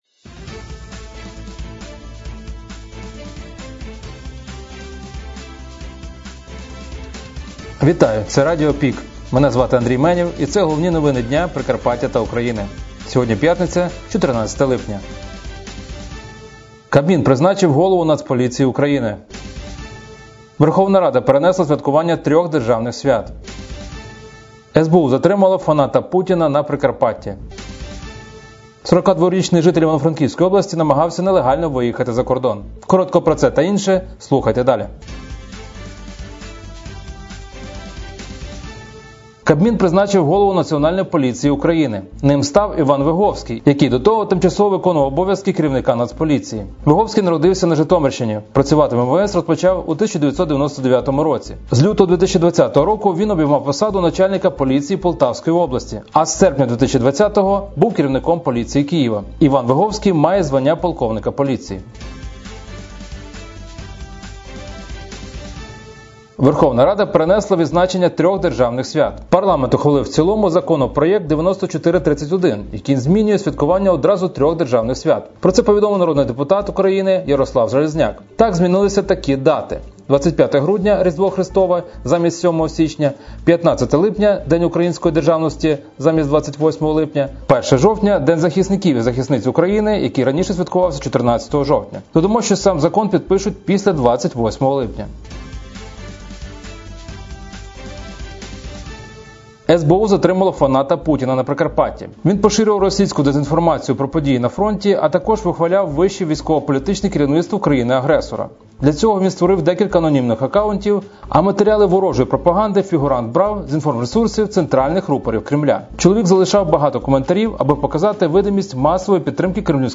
Пропонуємо вам актуальне за день - у радіоформаті.